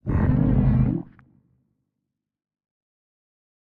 Minecraft Version Minecraft Version snapshot Latest Release | Latest Snapshot snapshot / assets / minecraft / sounds / mob / warden / ambient_6.ogg Compare With Compare With Latest Release | Latest Snapshot